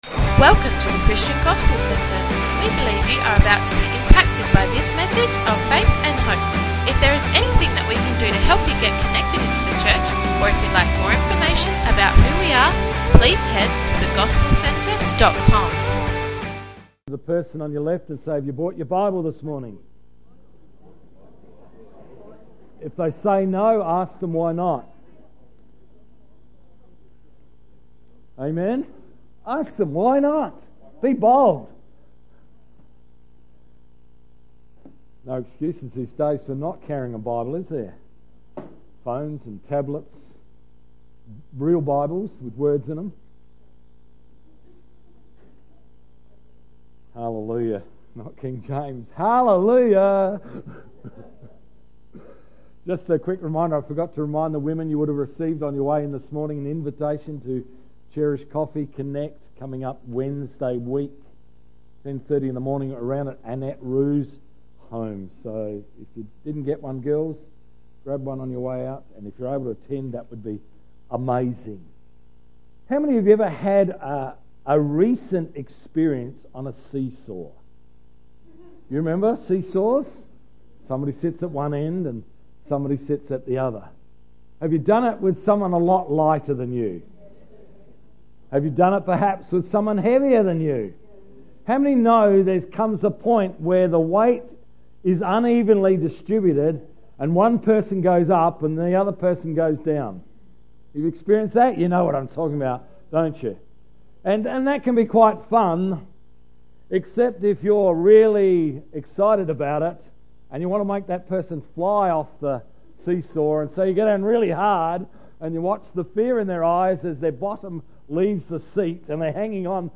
28th February 2016 – Morning Service